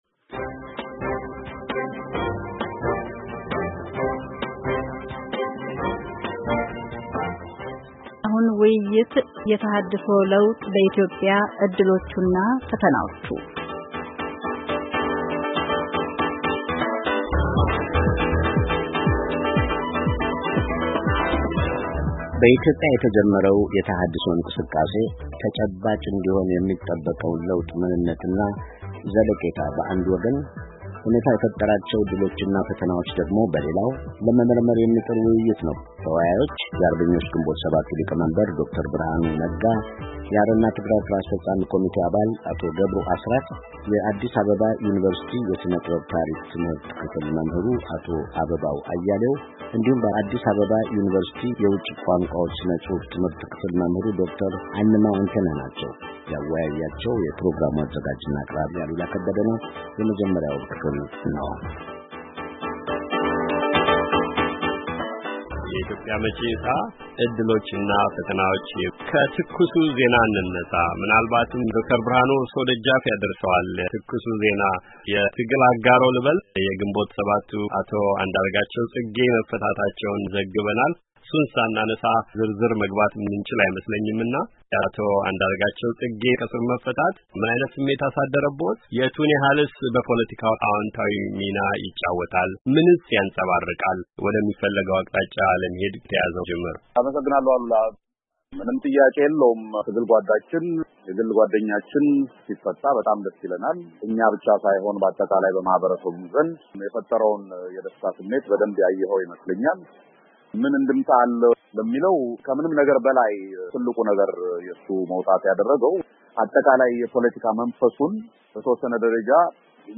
ውይይት፡ ፖለቲካዊ የተሃድሶ ለውጥ እርምጃዎች በኢትዮጵያ .. ዕድሎችና ፈተናዎች